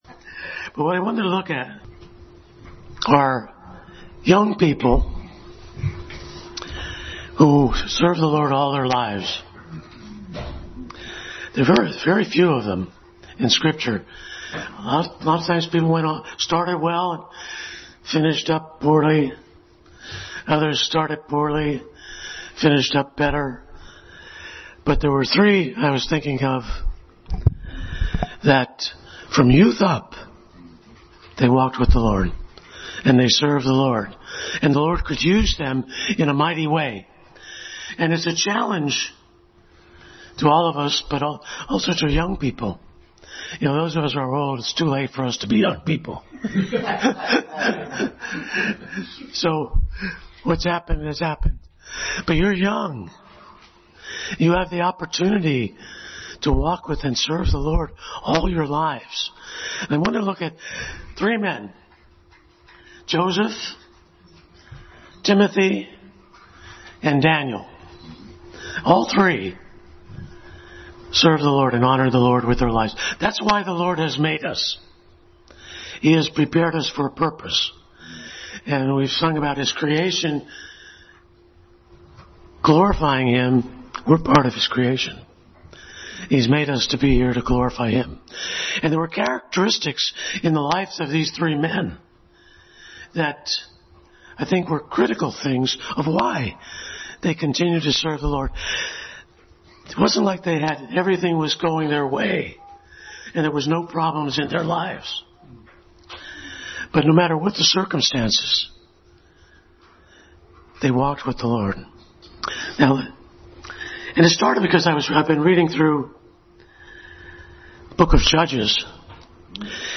Passage: Genesis 39:9, Hebrews 4:12-13, 2 Timothy 3:15, Daniel 1:8 Service Type: Family Bible Hour